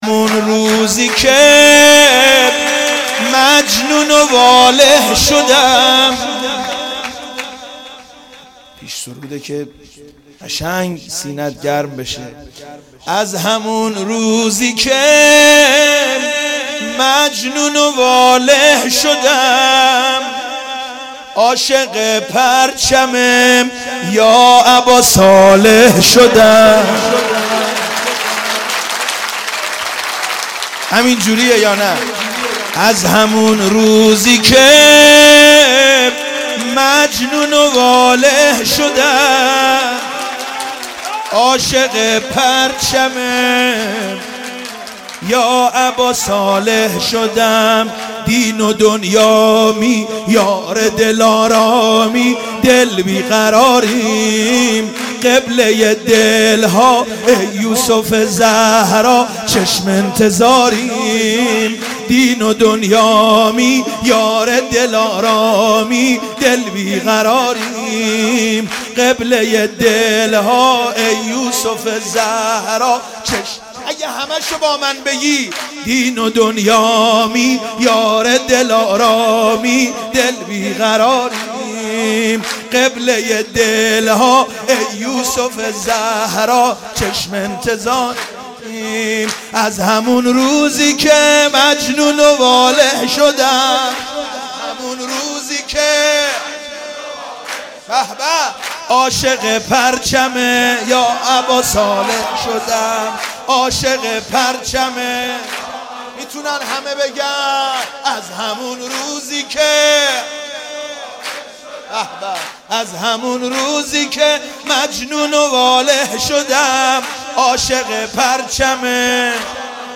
سرود مولودی